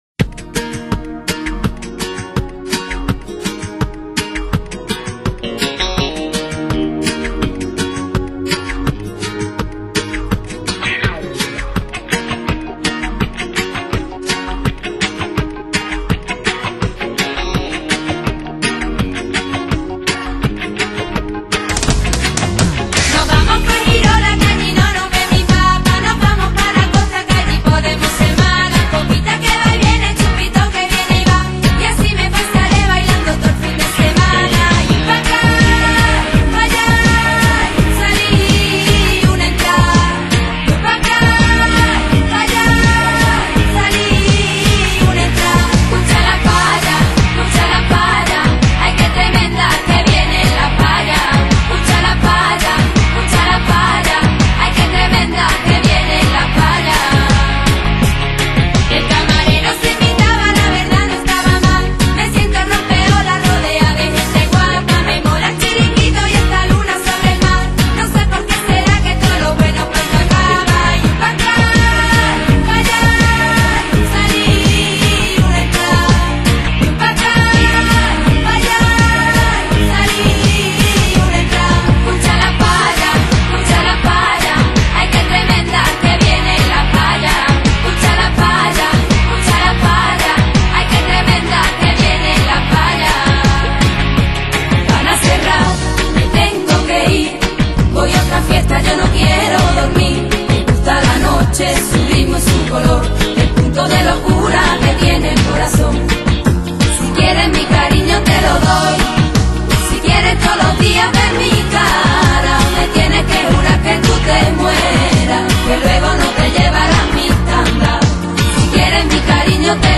来自南美的声音－－动感、激情！